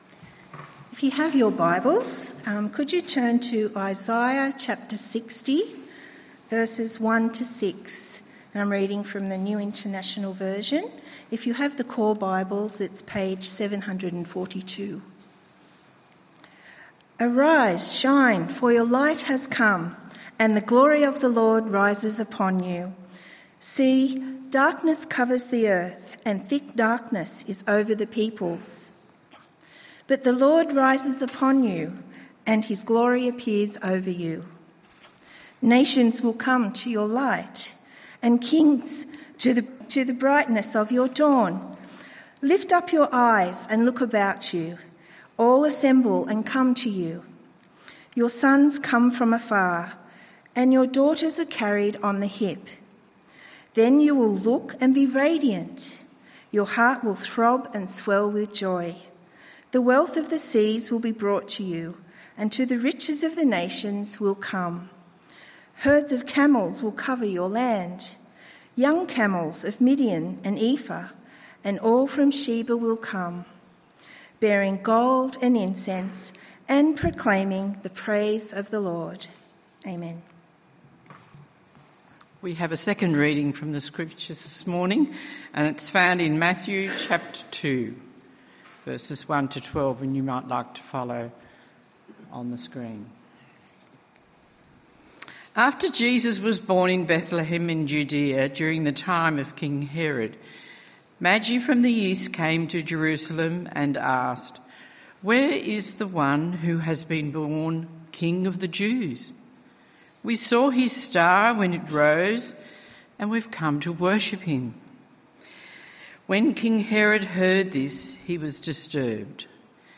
Bible readings and message from Epiphany Sunday. The Bible readings are taken from Isaiah 60:1-6 and Matthew 2:1-12.